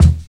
43 KICK 3.wav